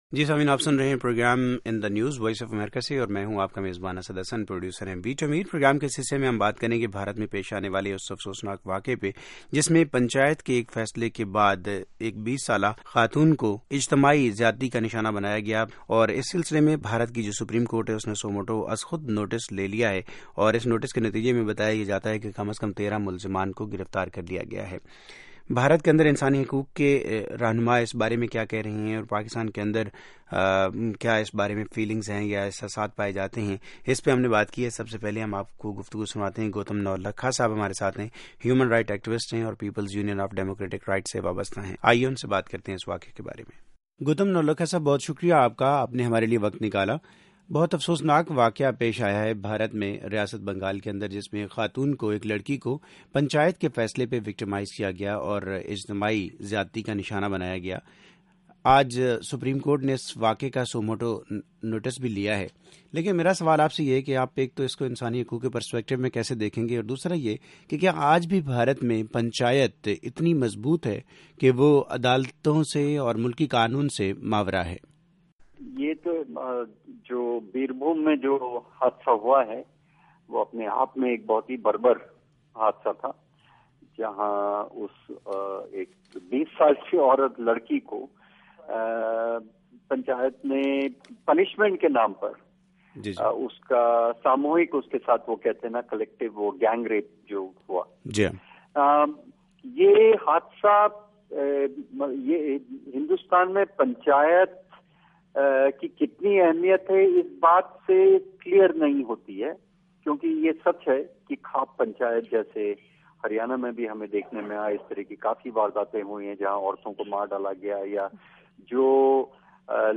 مختاراں مائی کے ساتھ خصوصی انٹرویو